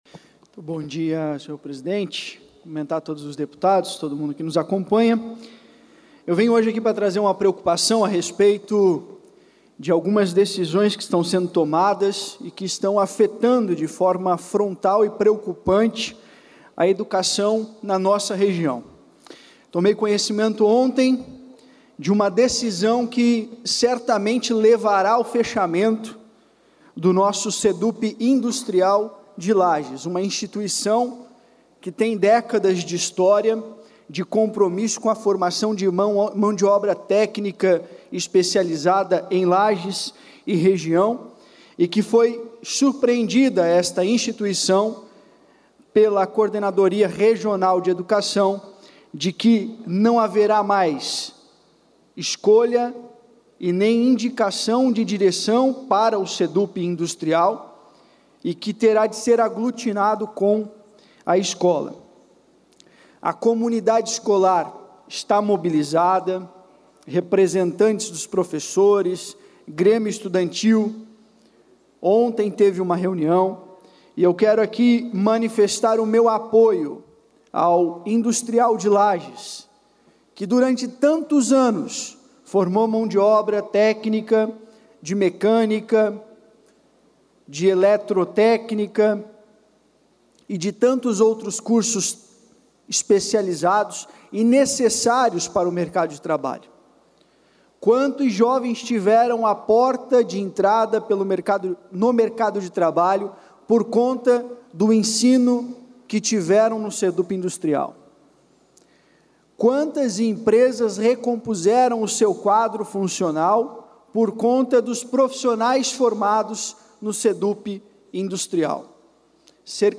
Pronunciamentos da sessão ordinária desta quinta-feira (9)